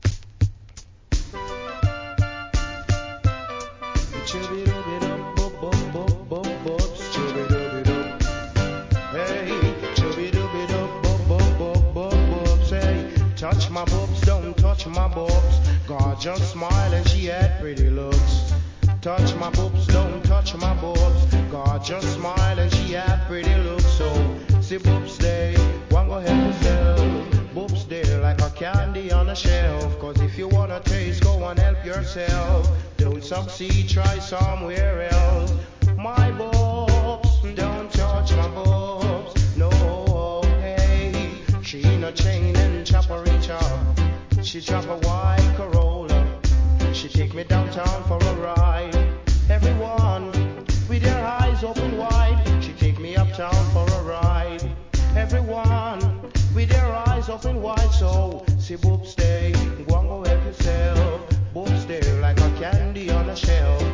REGGAE
'80s名DANCEHALL!!